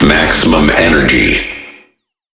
完美适用于打造震撼的低音、丰富的节奏纹理和地下音乐氛围。